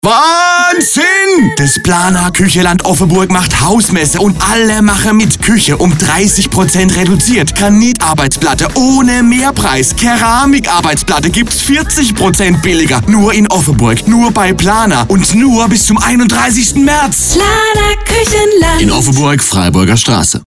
Badischer Werbesprecher - Synchronsprecher
Sie sind auf der Suche nach einer badischen Werbestimme?